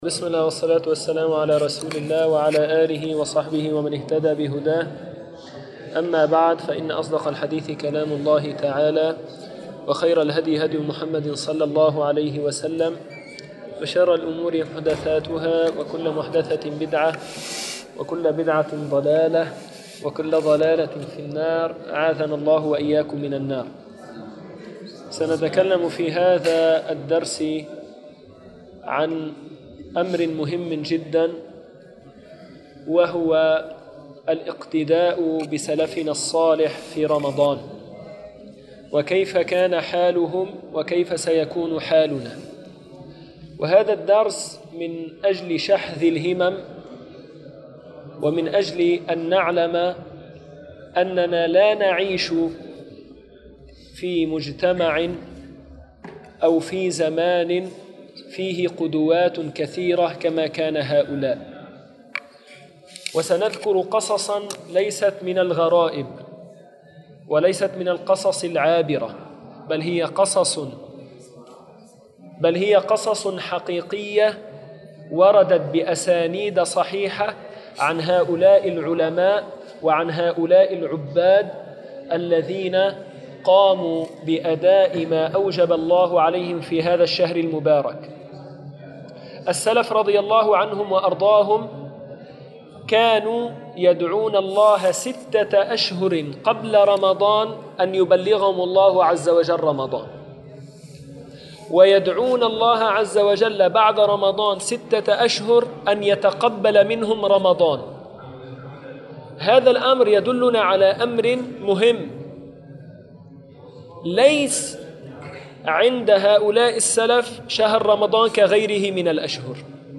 أحوال السلف والعابدين في شهر رمضان المكان: مسجد القلمون الغربي الزمان